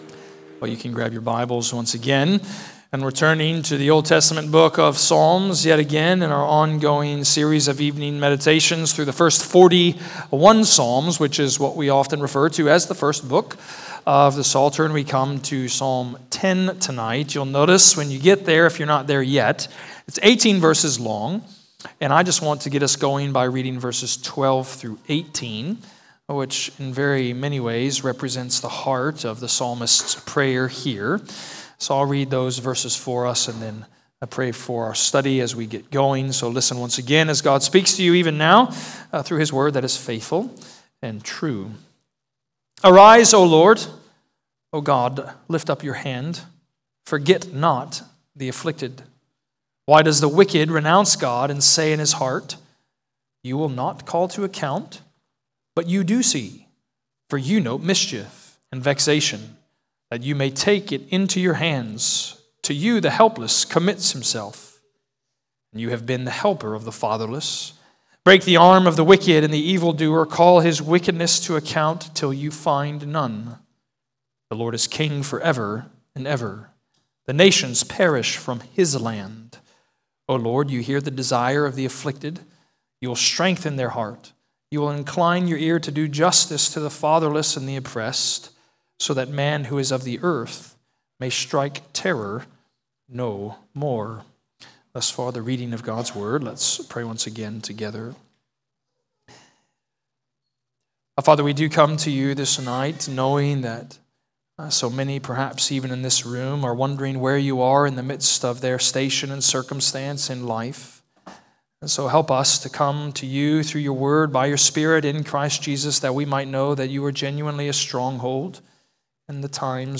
Download sermons from Redeemer Presbyterian Church in McKinney, TX.